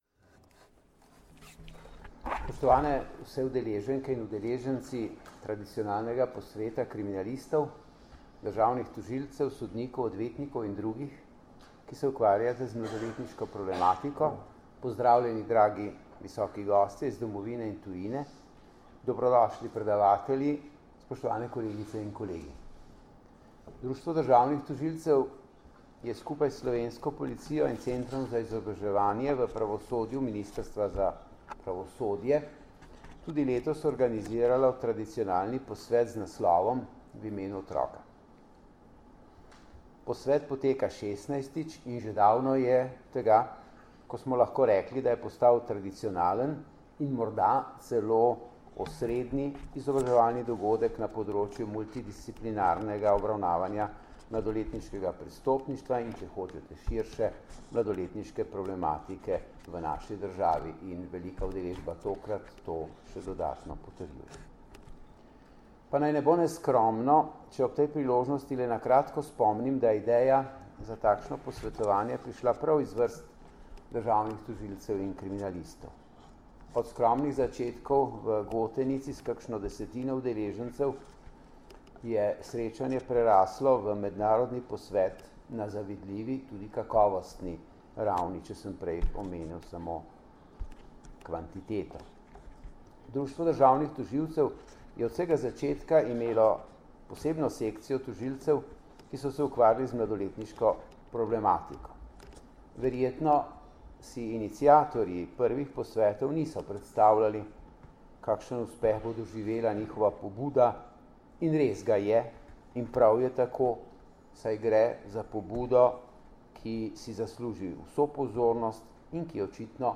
V Kongresnem centru Brdo, Predoslje, se je danes, 6. aprila 2017, pod naslovom V imenu otroka začel dvodnevni posvet na temo problematike poznavanja otroka za kvalitetnejšo obravnavo v postopkih, ki ga organizirata Policija in Društvo državnih tožilcev Slovenije v sodelovanju s Centrom za izobraževanje v pravosodju.
Zvočni posnetek nagovora dr. Zvonka Fišerja (mp3)